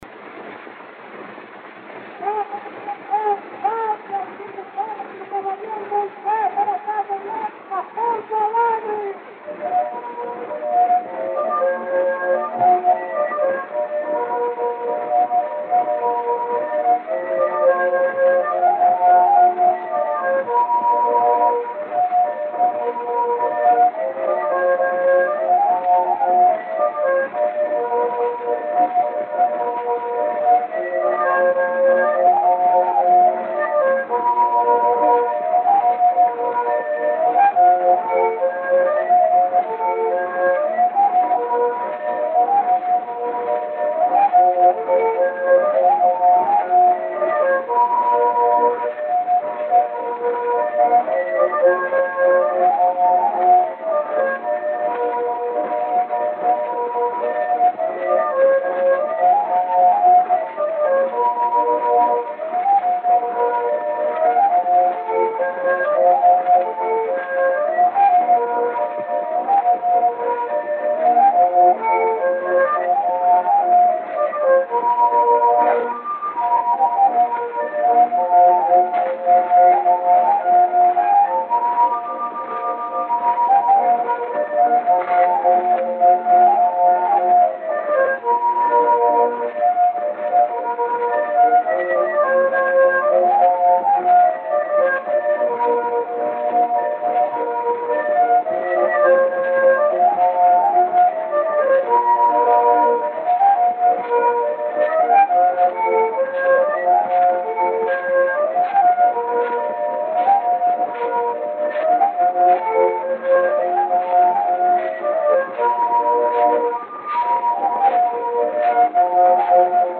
O gênero musical foi descrito como "Valsa".